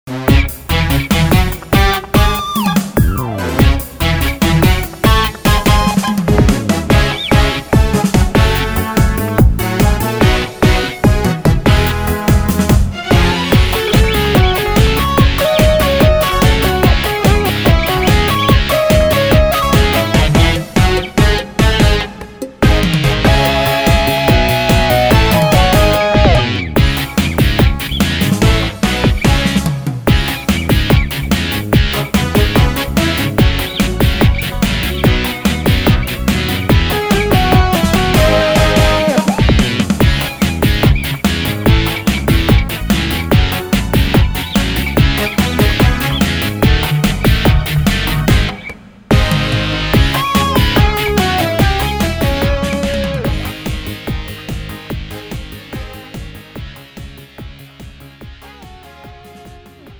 장르 가요 구분 Lite MR